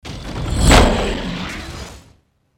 Fire (wood)
描述：Burning wood recorded. 1 minute
标签： flame hot Fieldrecorder Fire
声道立体声